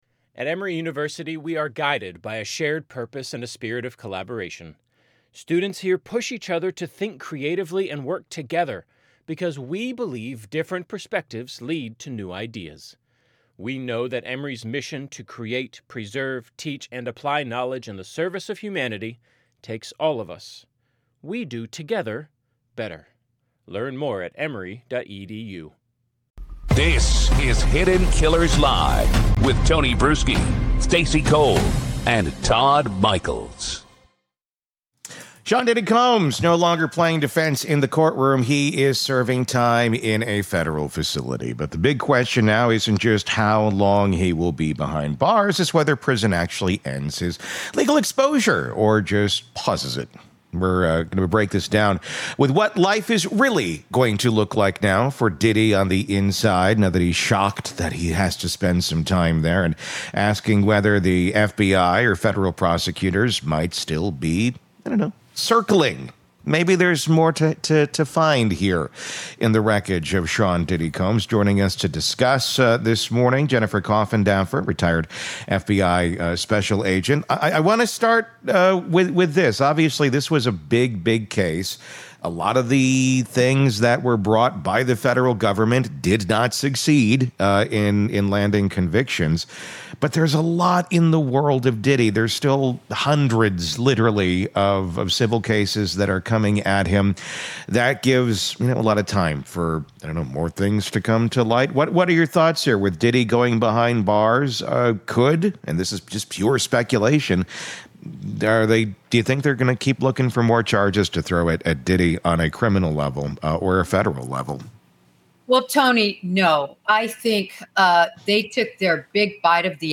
🔔 Subscribe for more deep-dive true crime interviews and expert breakdowns.